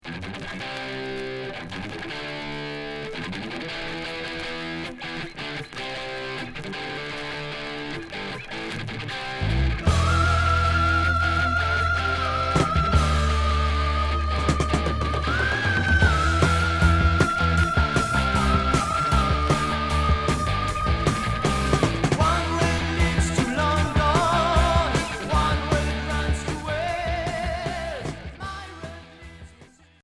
Hard progressif Unique 45t retour à l'accueil